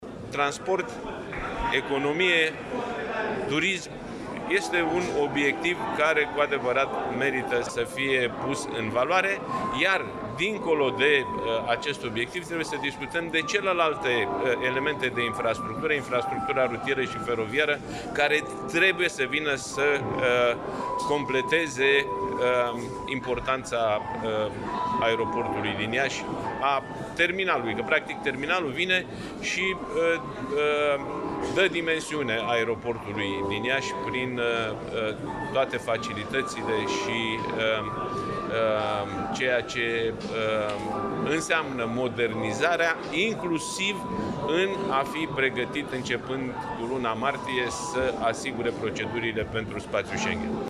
La Iași, a avut loc astăzi recepția lucrărilor la terminalul 4 al Aeroportului Internațional, investiţie care a fost finalizată la sfârşitul lunii decembrie 2023.
La festivități a fost prezent și președintele PNL, Nicolae Ciucă, care a evidențiat importanța acestui obiectiv pentru Iași: ”Transport, economie, turism este un obiectiv care cu adevărat merită să fie pus în valoare, iar dincolo de acest obiectiv trebuie să discutăm de celelalte elemente de infrastructură, infrastructura rutieră și feroviară care trebuie să vină să completeze importanța Aeroportului din Iași, a terminalului, că practic, terminalul vine și dă dimensiune Aeroportului din Iași prin toate facilitățile și ceea ce înseamnă modernizarea, inclusiv în a fi pregătit, începând cu luna martie, să asigure procedurile pentru spațiul Schengen.”